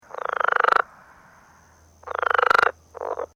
Rio Grande Leopard Frog - Lithobates berlandieri
The advertisement call of the Rio Grande Leopard Frog is a loud, short, low-pitched trill or rattle, lasting less than a second, given singly or in rapid sequences of 2 - 3 trills, made at night.
Sound This is a recording of the advertisement calls of a Rio Grande Leopard Frog recorded at night in Imperial County.